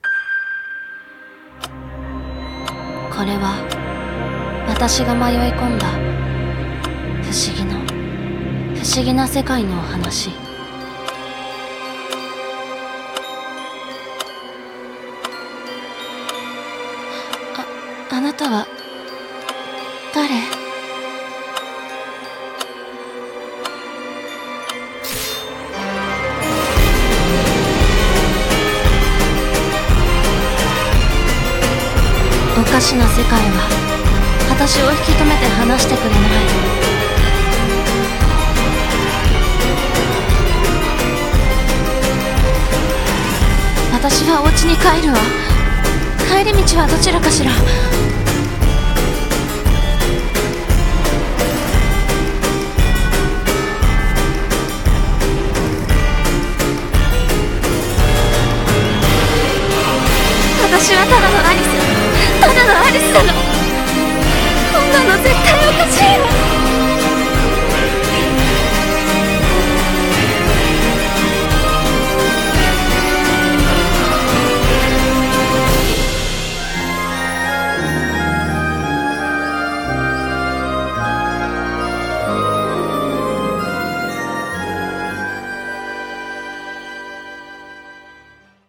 CM風声劇「私のワンダーランド」